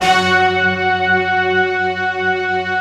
Index of /90_sSampleCDs/Optical Media International - Sonic Images Library/SI1_StaccatoOrch/SI1_Sfz Orchest